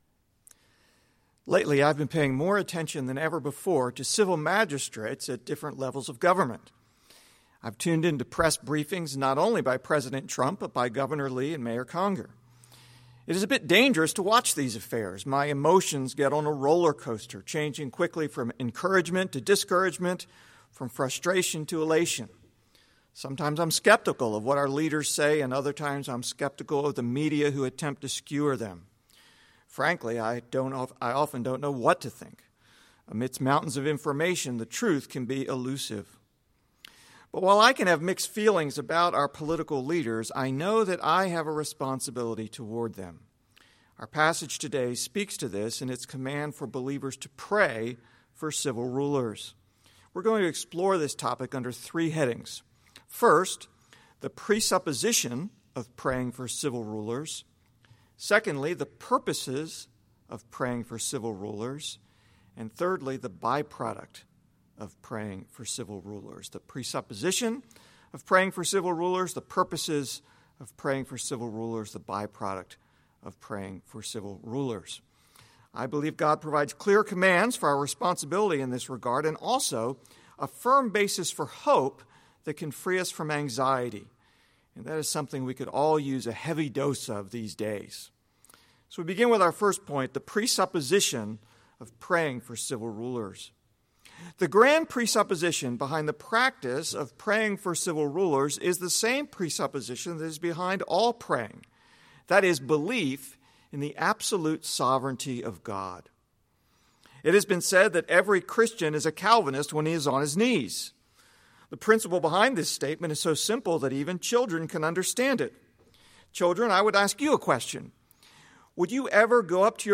In a time when we are hearing much from civil rulers at every level of government, it is good to be reminded of our responsibility toward them. This message was recorded for use in at-home worship.